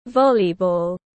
Môn bóng chuyền tiếng anh gọi là volleyball, phiên âm tiếng anh đọc là /ˈvɑːlibɔːl/ .
Volleyball /ˈvɑːlibɔːl/
Volleyball.mp3